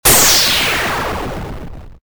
corexplode.ogg